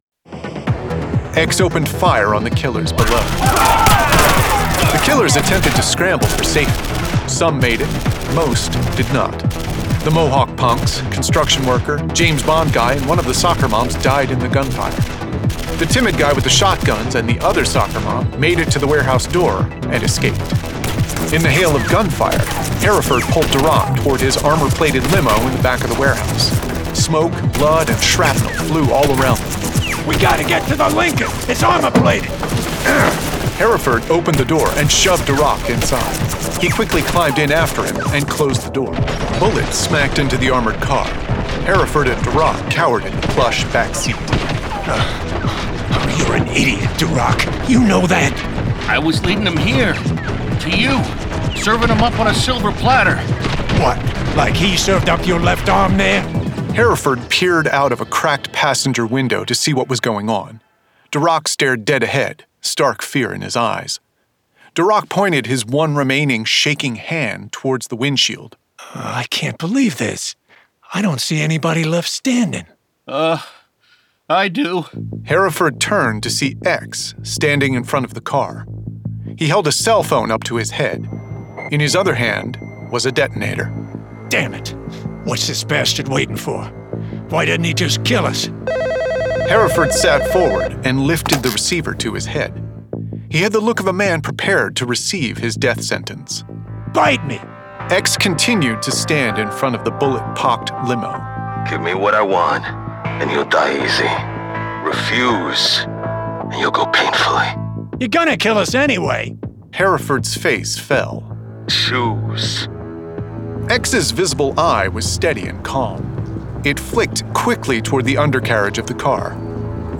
Full Cast. Cinematic Music. Sound Effects.
[Dramatized Adaptation]
Adapted from the issues and produced with a full cast of actors, immersive sound effects and cinematic music.